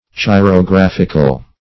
Search Result for " chirographical" : The Collaborative International Dictionary of English v.0.48: Chirographic \Chi`ro*graph"ic\, Chirographical \Chi`ro*graph"ic*al\a. Of or pertaining to chirography.
chirographical.mp3